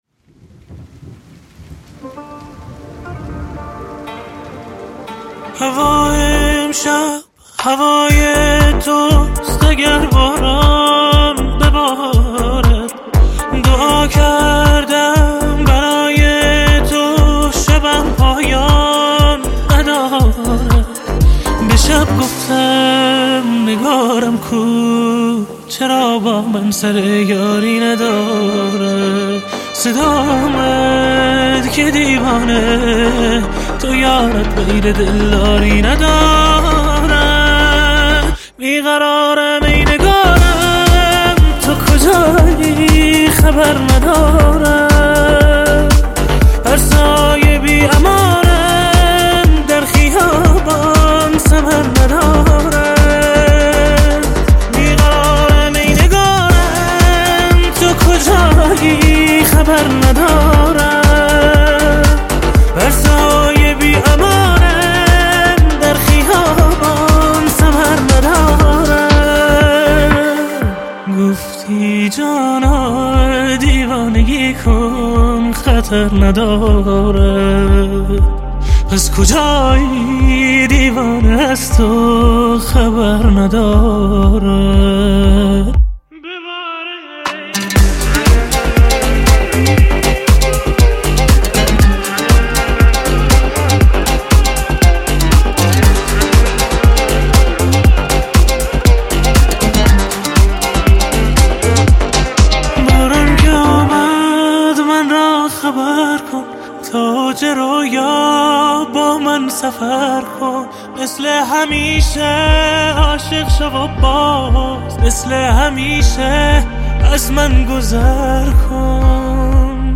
دانلود آهنگ شاد جدید